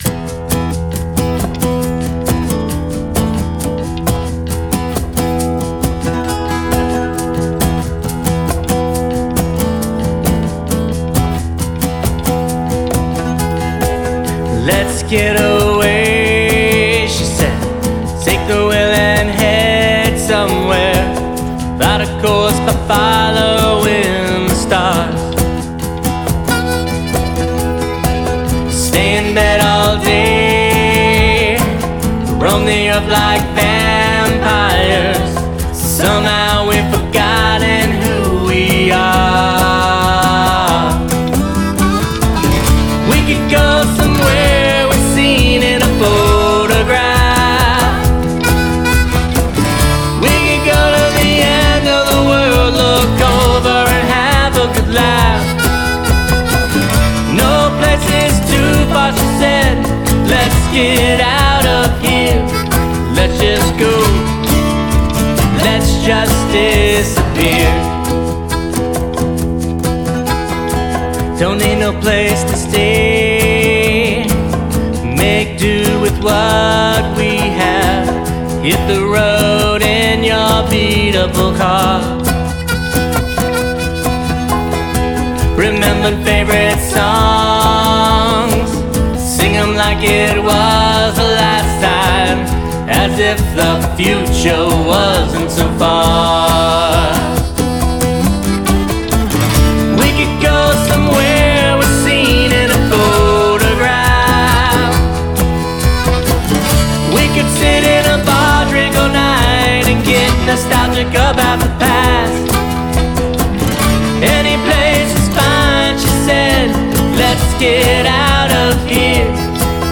Through delicate guitar arrangements and heartfelt lyrics